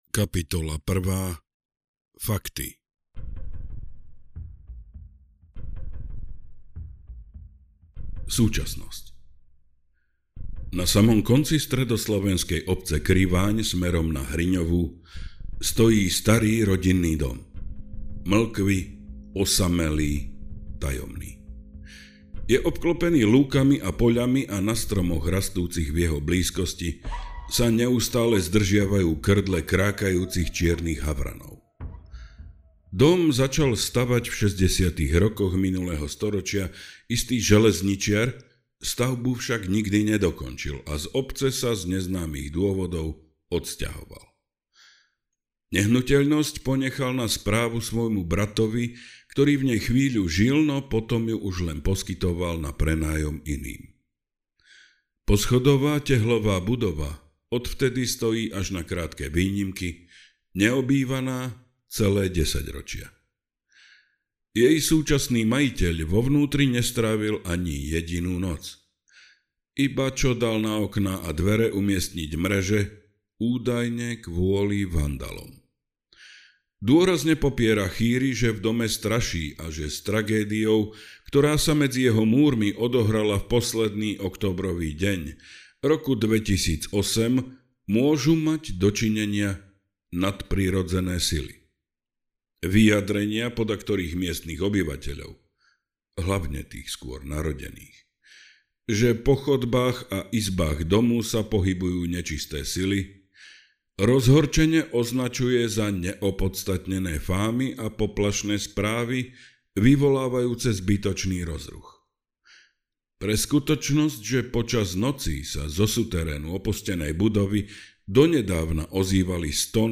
Budeme rádi, když s pomocí recenze dáte ostatním vědět, jak se vám audiokniha líbila.